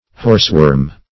Horseworm \Horse"worm`\, n. The larva of a botfly.